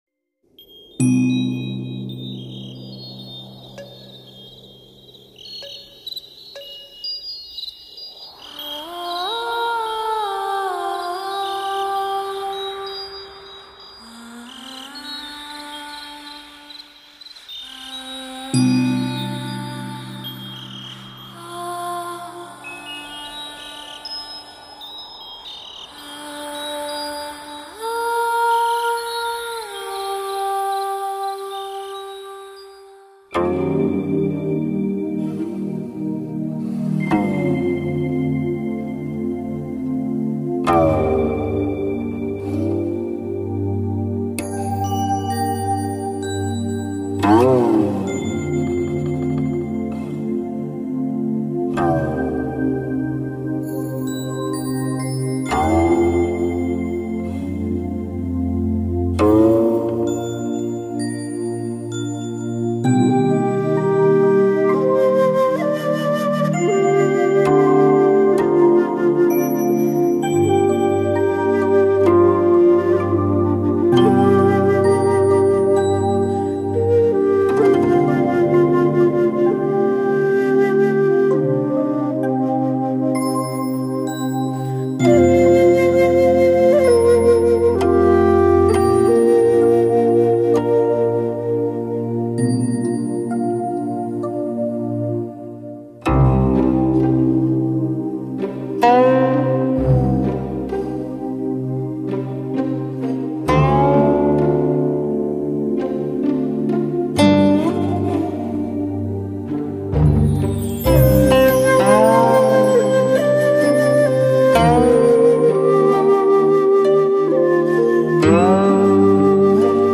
音乐类型: 民族音乐